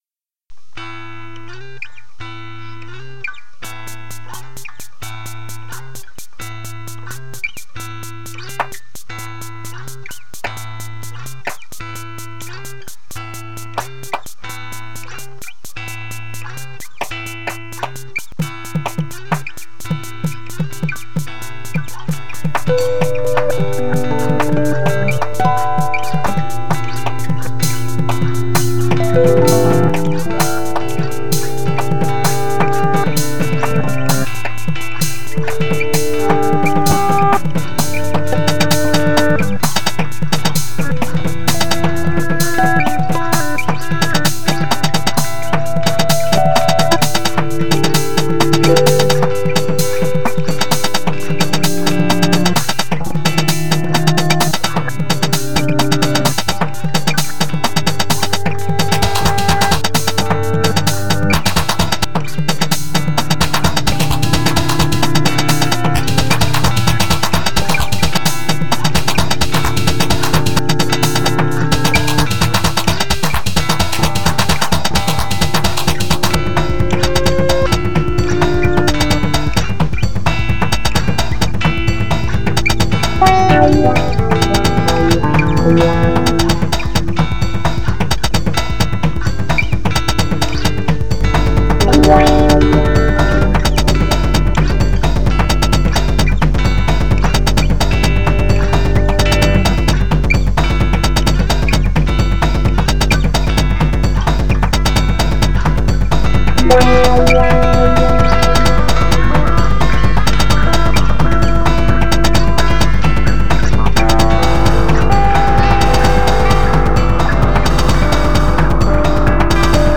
all Instruments